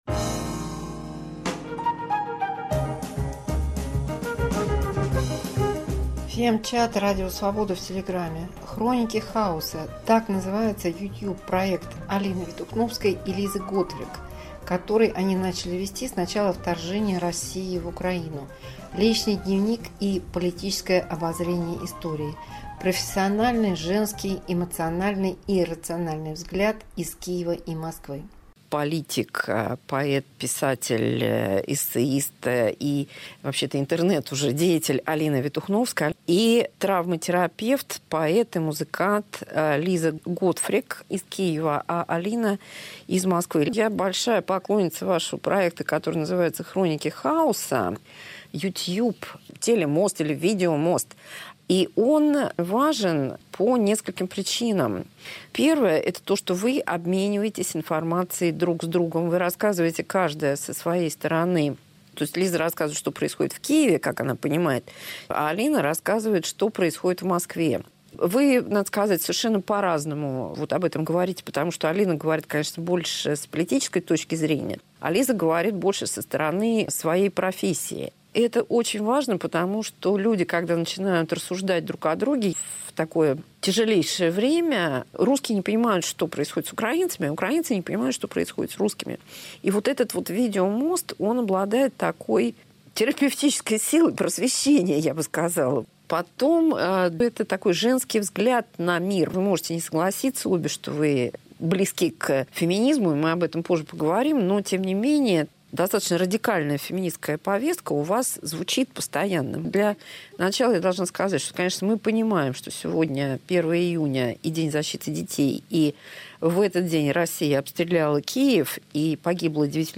Диалог московского писателя и киевского травмотерапевта
Видеомост Киев-Москва. “Шизоидный портал” и “расстройство очевидности” – диагнозы, которые ставят собеседницы “русскому миру” с момента его вторжения в Украину